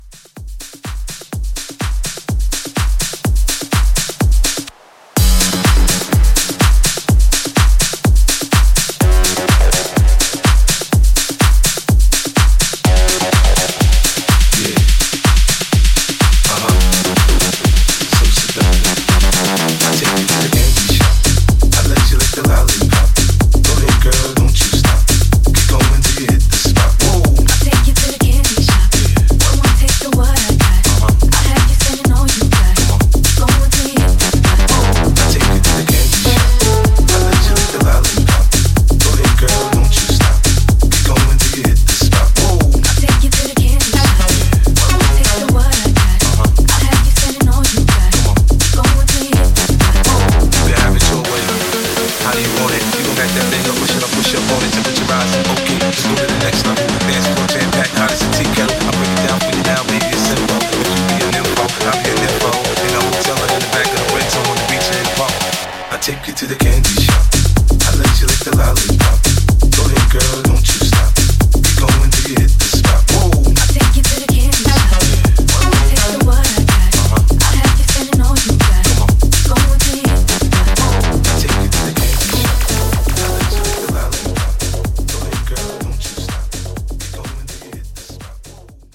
Genre: AFROBEAT
Clean BPM: 130 Time